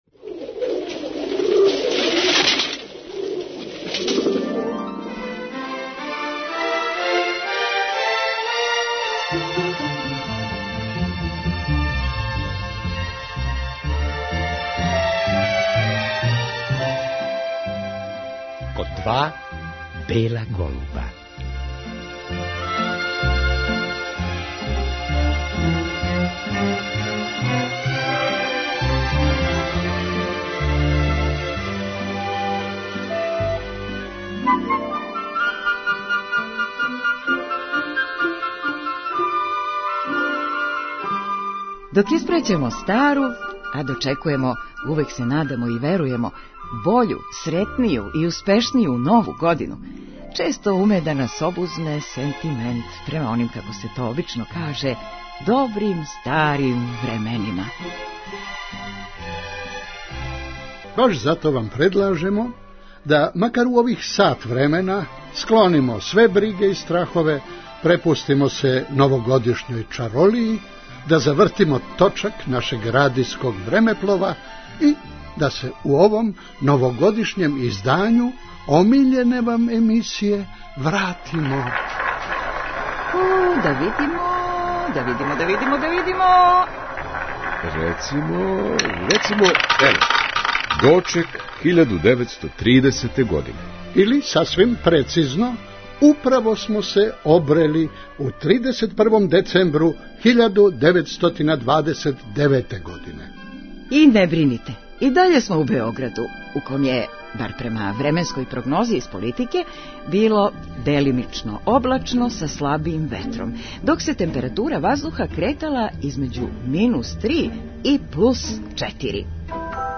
Од документарних делића давнопрошле београдске предновогодишње свакодневице и уз нешто наше, пословично издашне а добронамерне маште, склопили смо за вас једну сасвим безазлену и пре свега веселу новогодишњу радијску играрију са много лепе музике и са сретним завршетком.